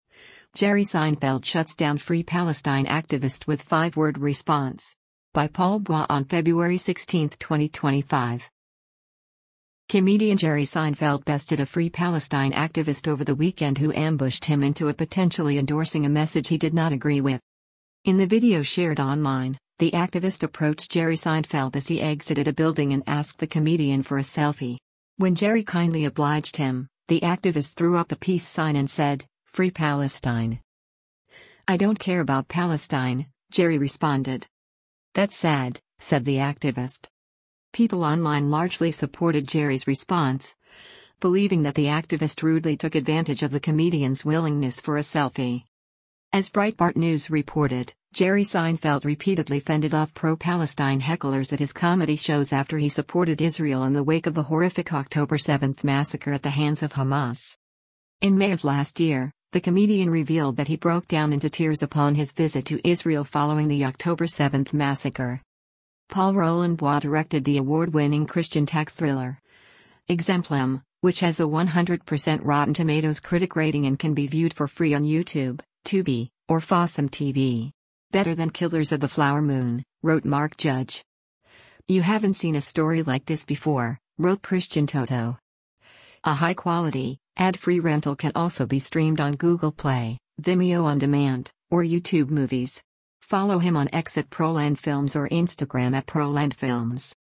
In the video shared online, the activist approached Jerry Seinfeld as he exited a building and asked the comedian for a selfie.